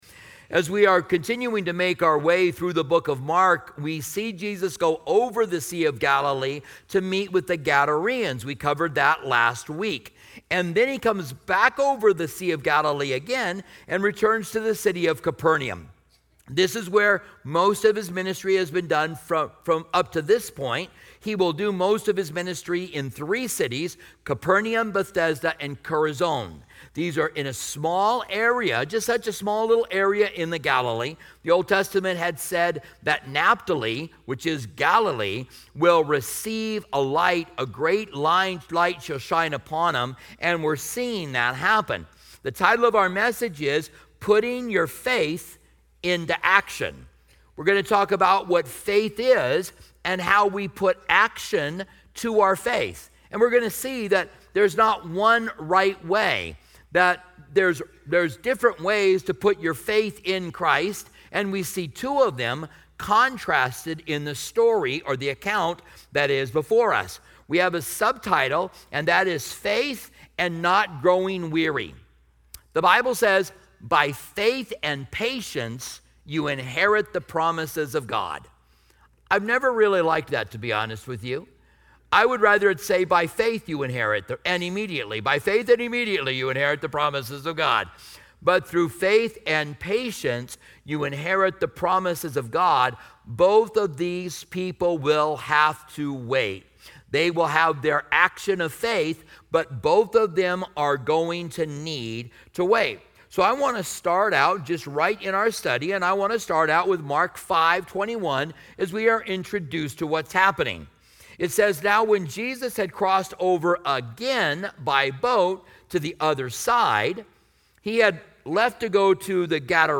This message emphasizes the importance of active faith—illustrating how both individuals, despite their different backgrounds, demonstrated unwavering trust in Jesus during desperate times. Explore key themes such as the necessity of bold faith, persevering through delays, the transformative power of Jesus’ touch, and overcoming fear with belief. This sermon is designed to encourage anyone grappling with personal struggles or seeking hope in challenging situations.